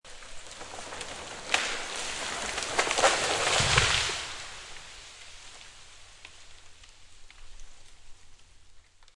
Download Free Tree Falling Sound Effects
Tree Falling